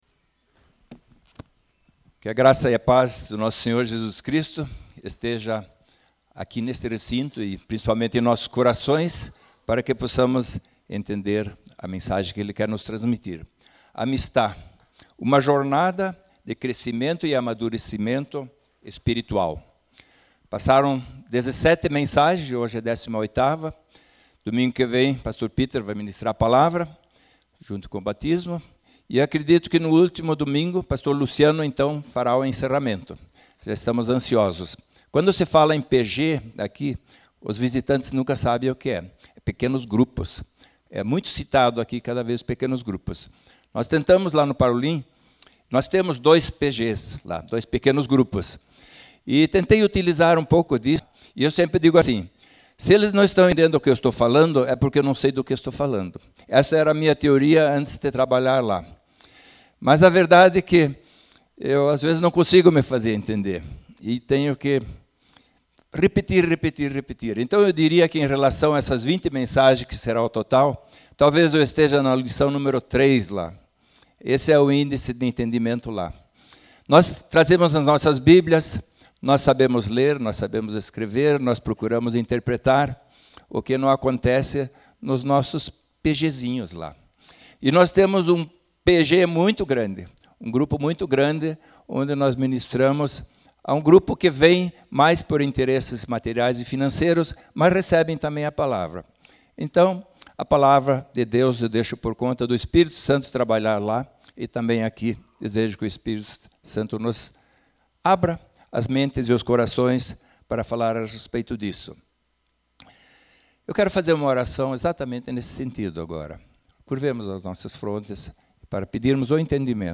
Ouça a mensagem que faz parte da SÉRIE AMISTAD - palavras que ensinam nos aproximar de Deus como amigos.